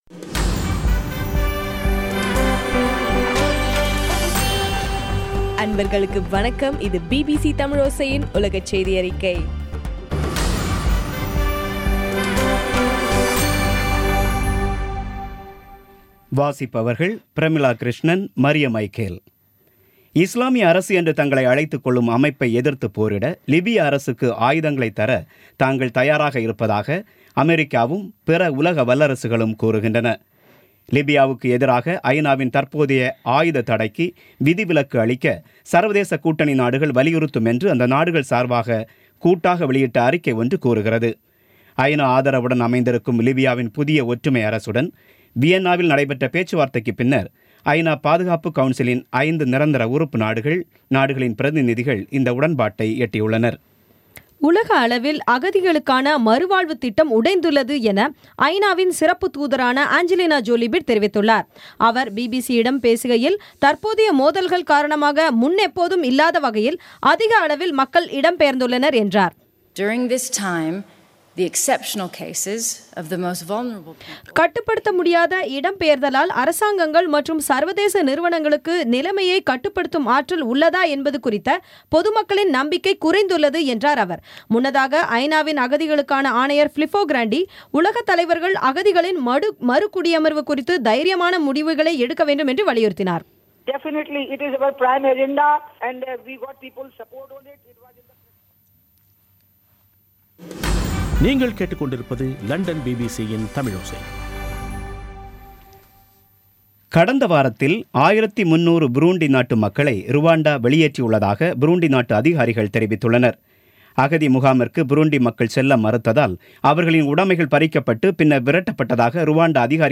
இன்றைய (மே 16ம் தேதி ) பிபிசி செய்தியறிக்கை